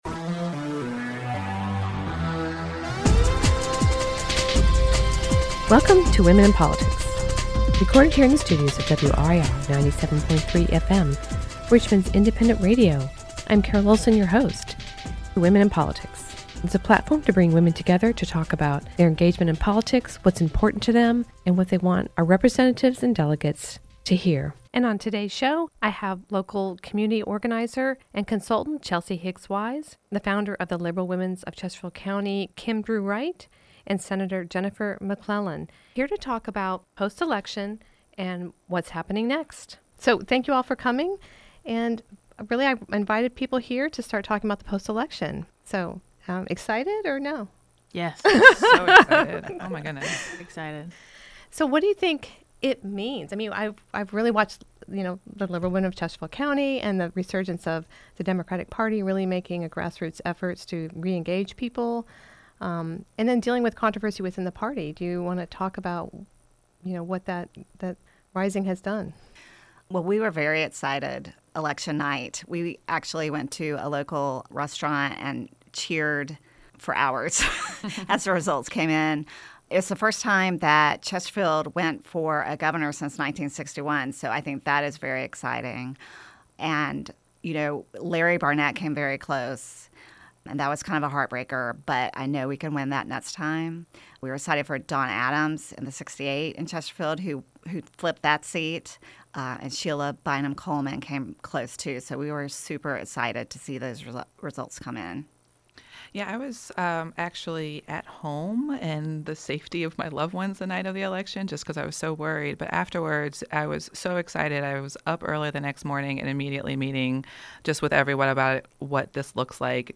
Women and Politics: A Post-election Conversation.